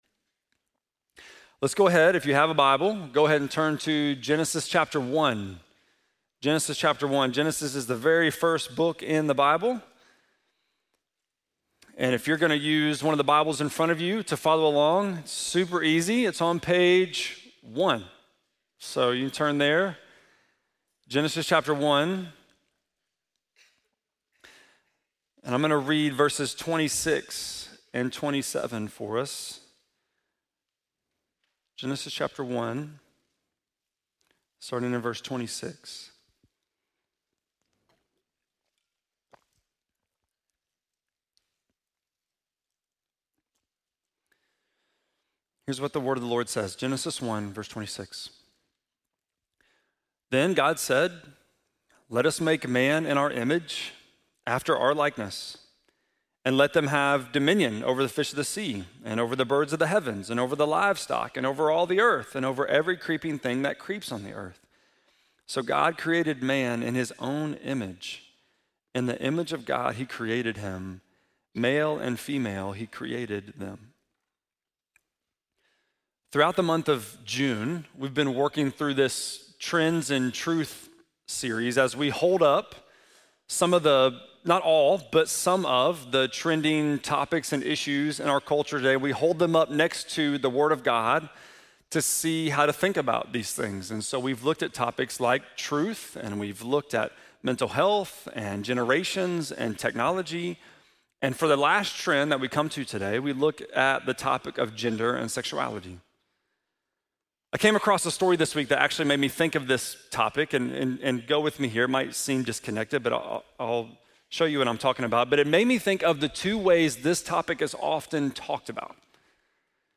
6.29-sermon.mp3